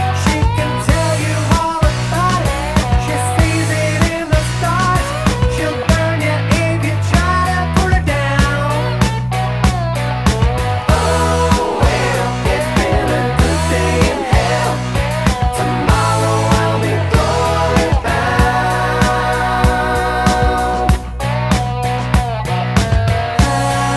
Minus Guitars Soft Rock 4:27 Buy £1.50